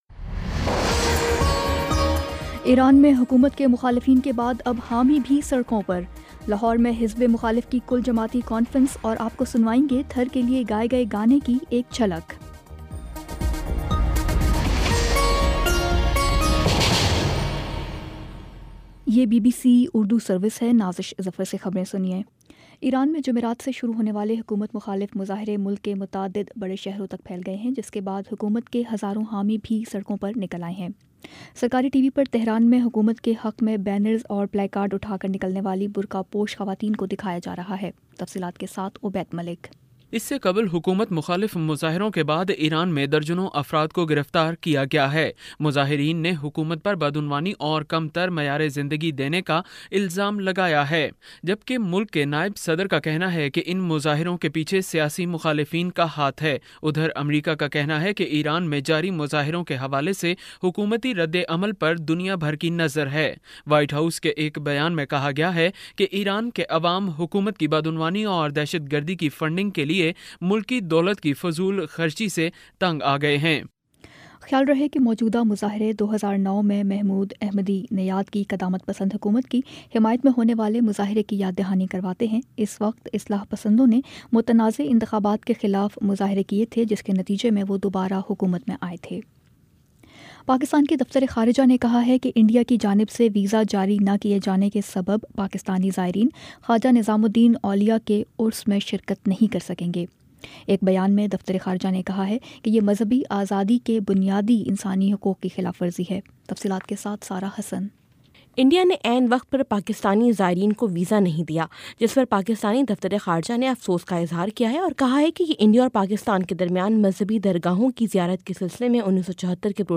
دسمبر 30 : شام پانچ بجے کا نیوز بُلیٹن